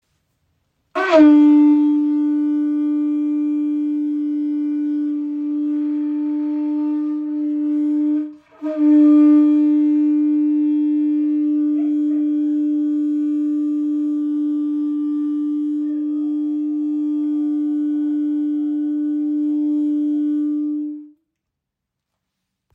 • Poliert: Sanftes Mundstück für komfortables Spielen bei kraftvollem, tragendem Klang.
Kuhhorn - Signalhorn poliert Nr. 1